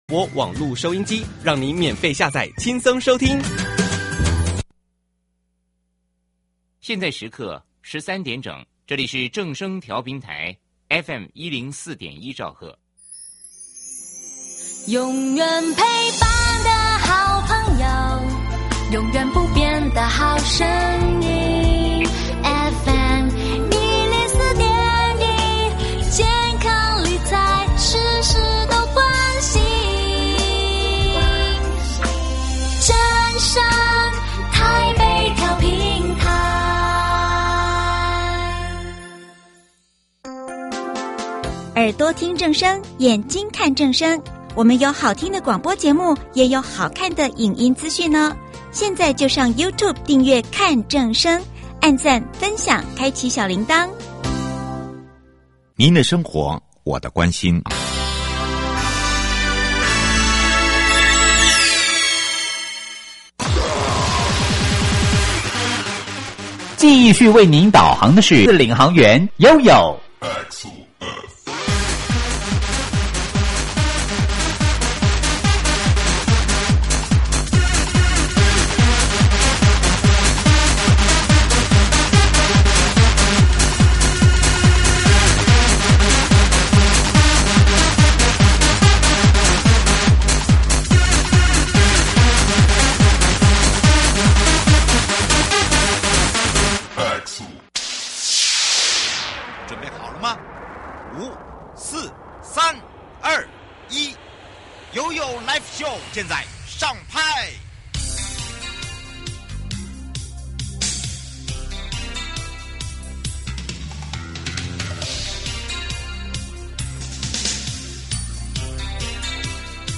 今天再次邀請到基隆市工務處 簡翊哲 處長，和我們深入聊聊：這些工程背後的專業思考、對市民生活真正帶來什麼改變以及，基隆交通正在往哪裡走！